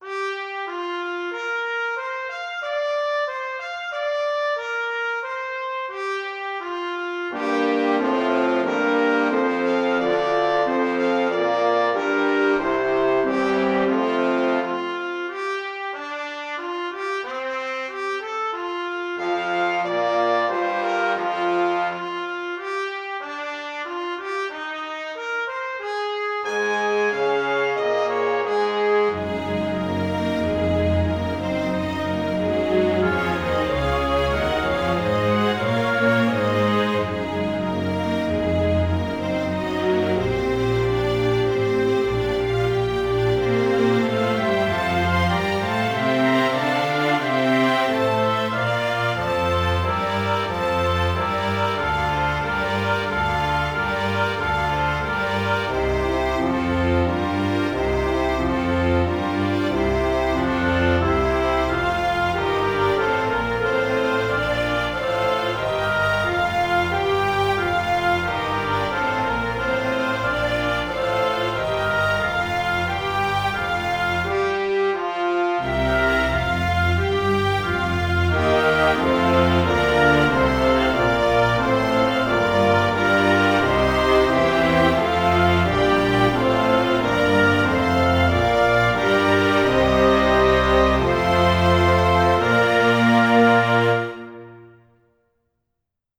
Finally there's an excerpt from Promenade from Pictures at an Exhibition by Mussorgsky, arranged for an orchestra.
No processing of any kind, all the reverb is present in the samples.